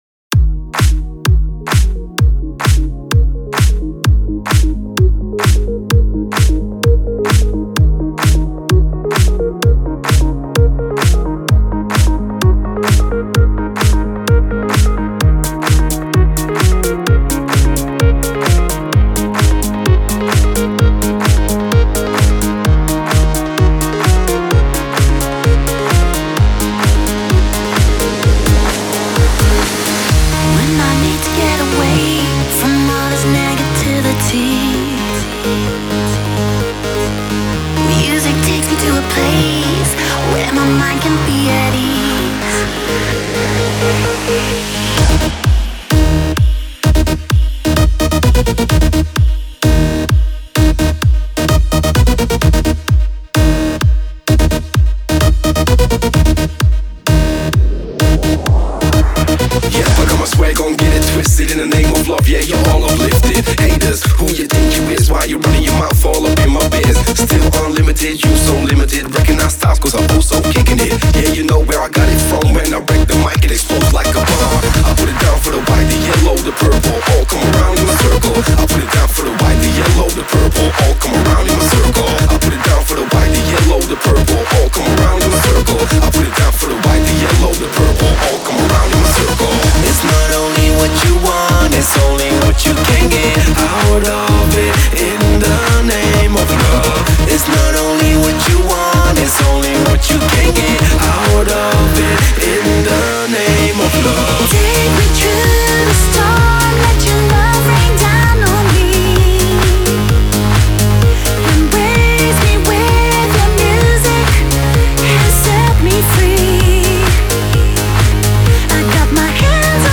Жанр:Dance